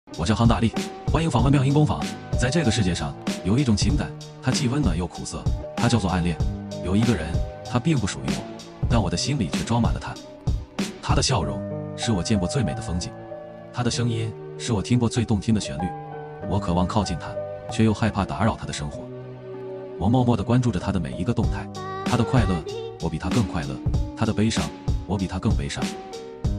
所以，我就在原基础上改了一点点效果，使推理后的效果有磁性感。